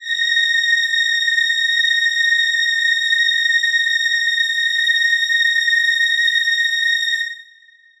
Choir Piano
A#6.wav